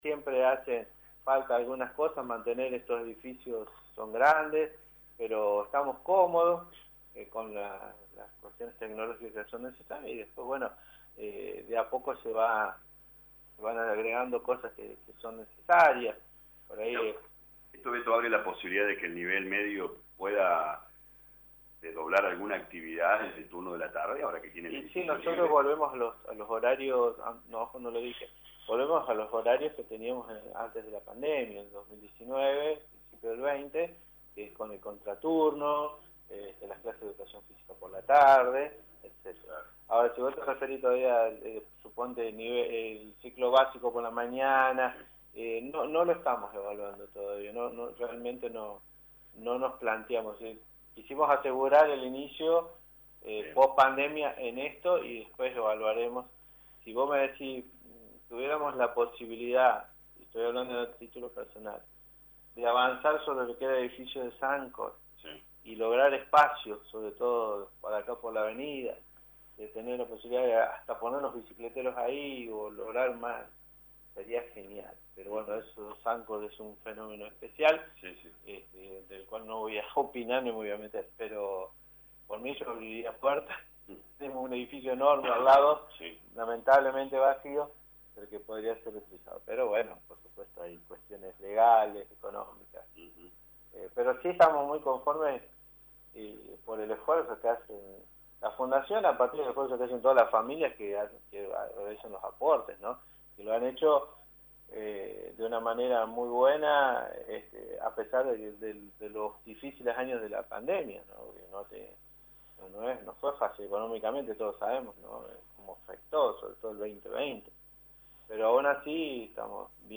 En diálogo con LA RADIO 102.9 FM